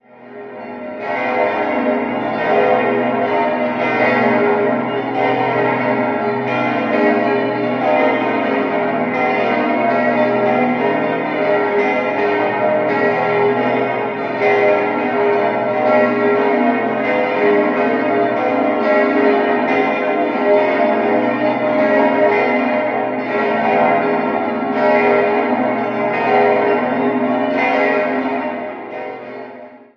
10-stimmiges Geläute: as°-b°-c'-es'-f'-as'-b'-des''-f''-b''
Das glockenreichste Geläute im Bistum Augsburg. Die sechs kleineren Glocken bilden zusammen ein herrlich frisches Teilgeläute, die drei großen mit ihrem schweren, metallischen Klang dominieren das Plenum.
Augsburg_Basilika.mp3